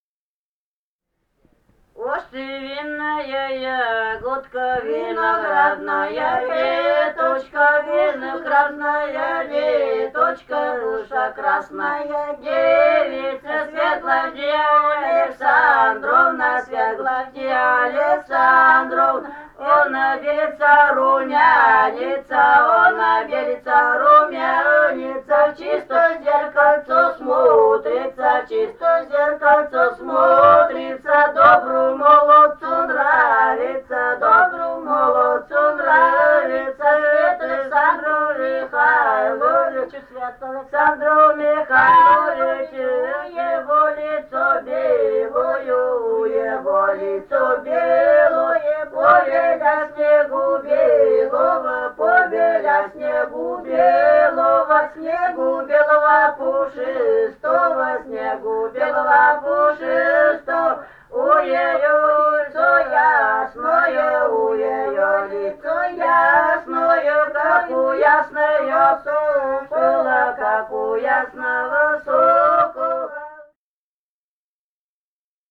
Этномузыкологические исследования и полевые материалы
«Уж ты винная ягодка» (свадебная).
Пермский край, д. Подвигаловка Кунгурского района, 1968 г. И1079-21